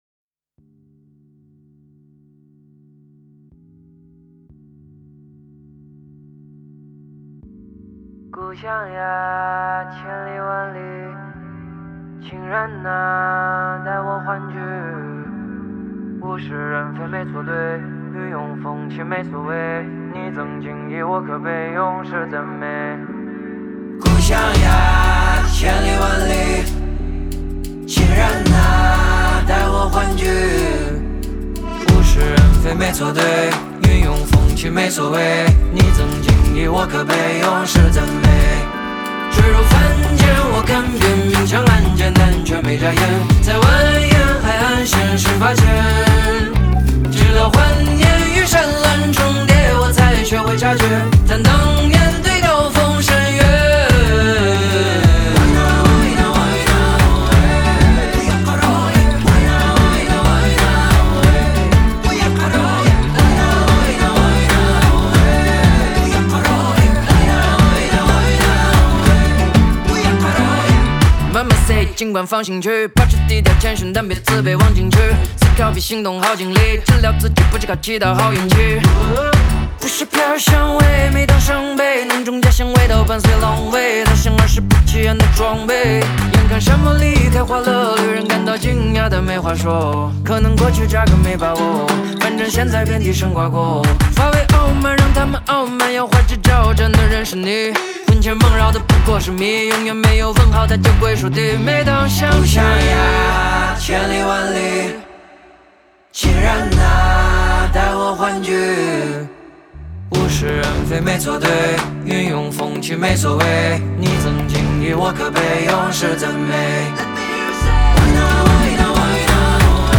Ps：在线试听为压缩音质节选
新疆的民族风味十足